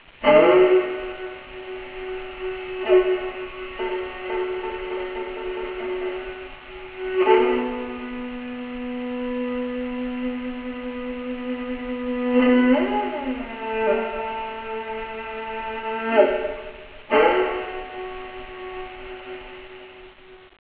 馬頭琴の魅力たっぷりのCDです。